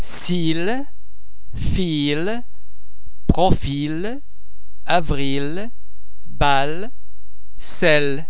Note: The French [ l ] is normally pronounced in word final position TOO !
[ l ] (word final)- as in